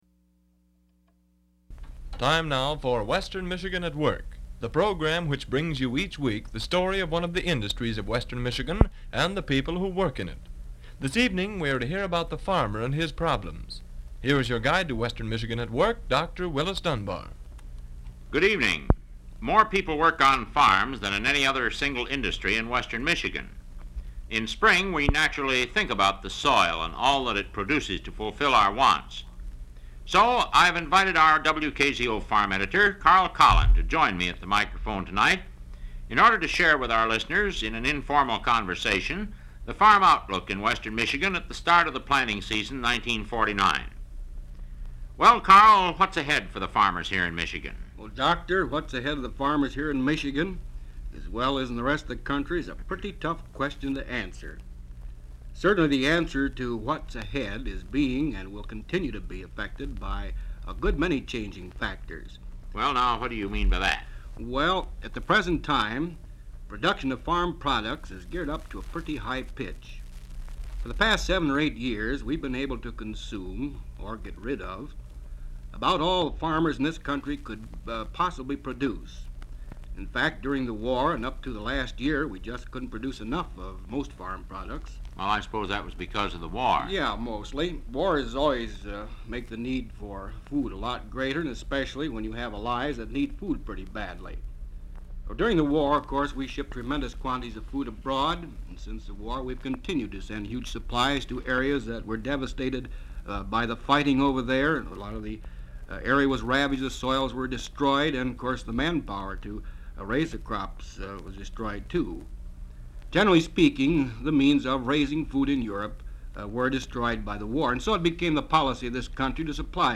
WKZO transcription disc collection